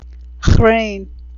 Chrain: (khrain) horseradish.